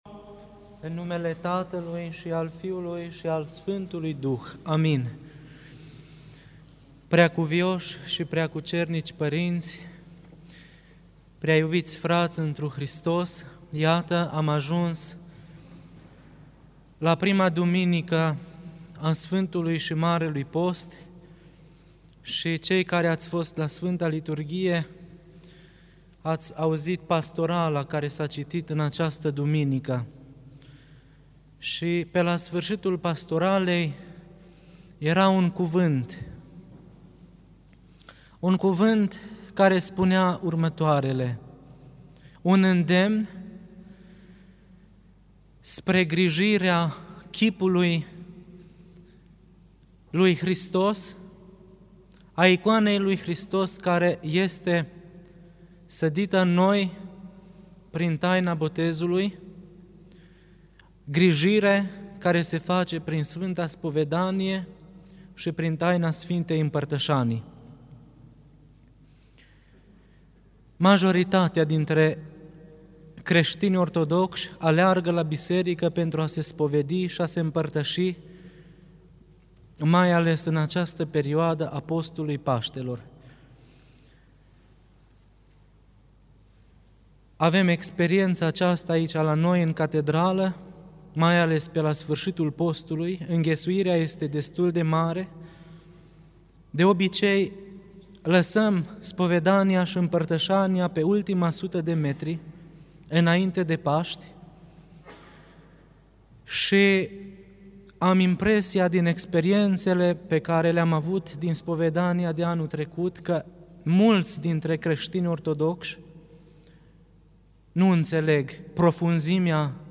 Predică la Duminica întâi din Post